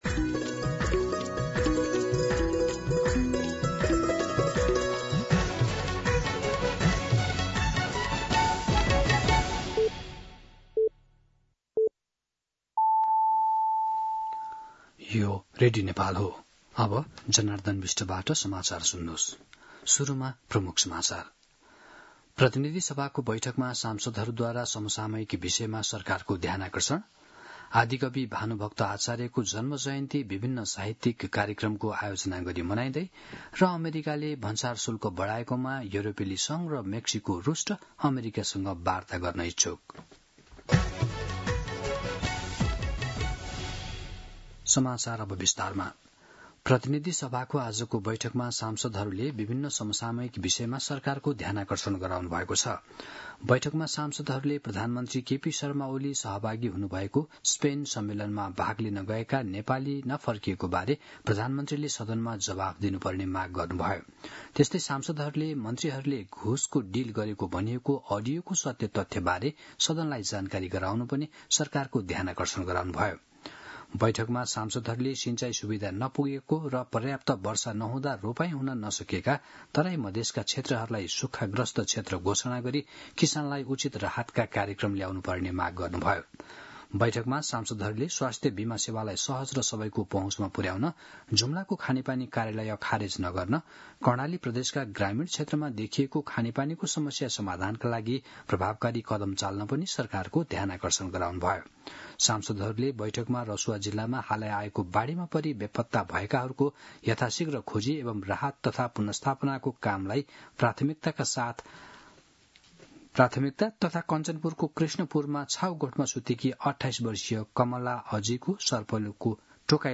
दिउँसो ३ बजेको नेपाली समाचार : २९ असार , २०८२
3-pm-News-3-29.mp3